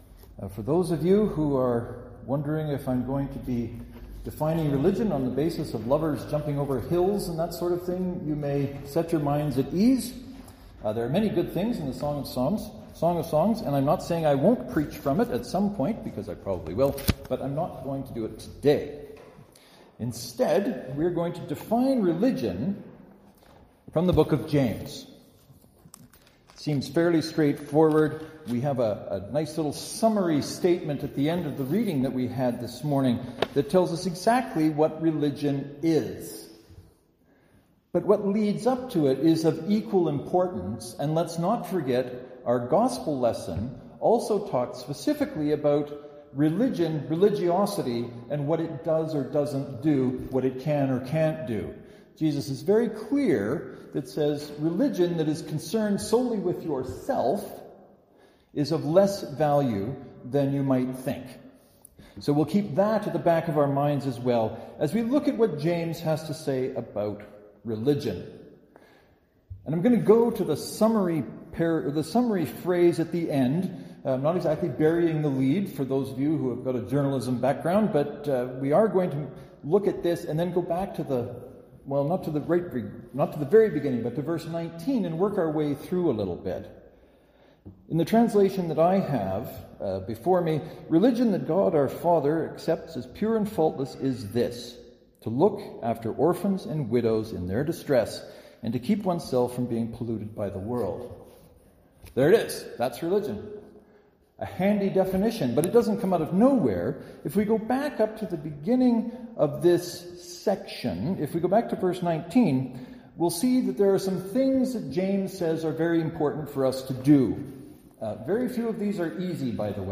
I promise you I didn’t read really, rreeaallyyyy, slowly.